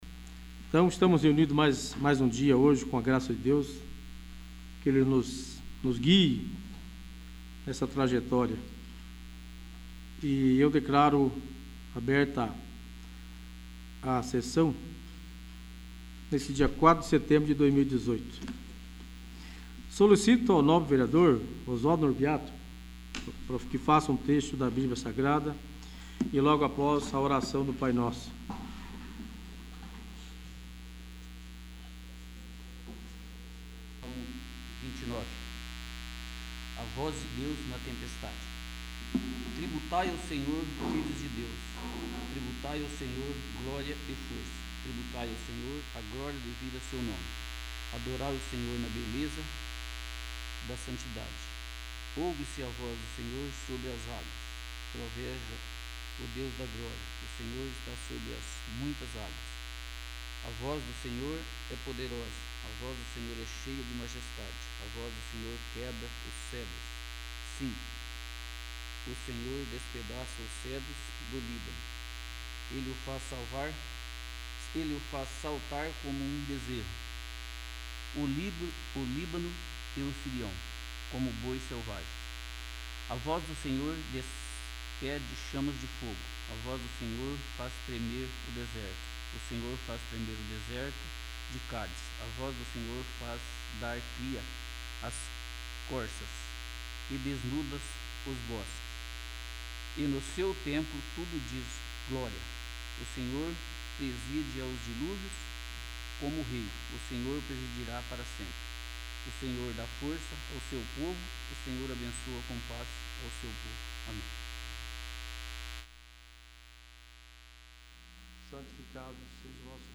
26º. Sessão Ordinária